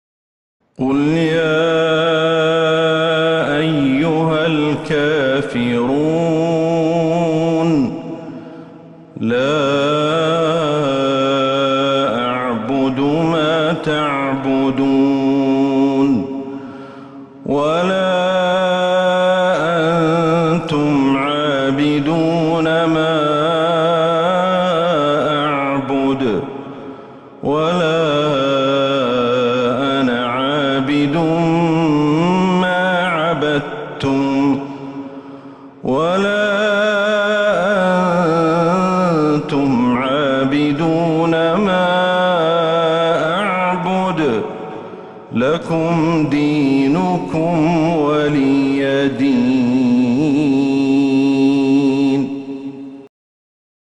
سورة الكافرون Surat Al-Kafiroon > المصحف المرتل من المسجد النبوي > المصحف - تلاوات الشيخ أحمد الحذيفي